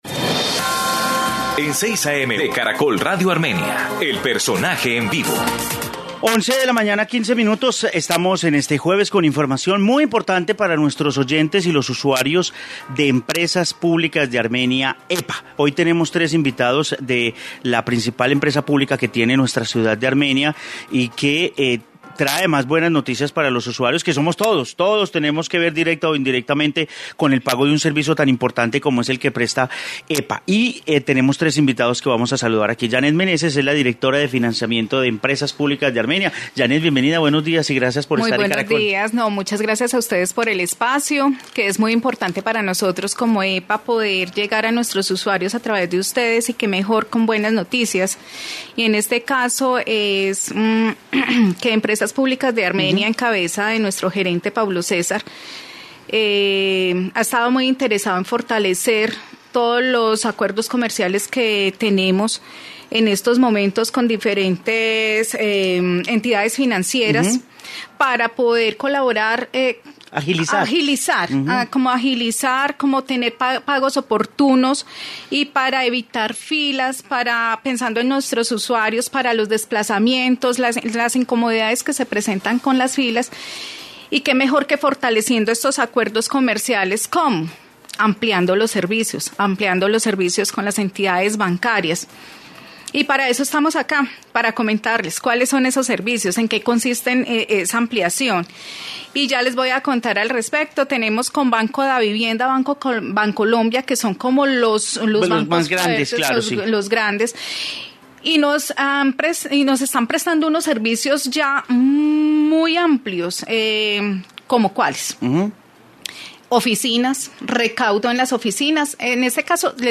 Informe entrevista funcionarios EPA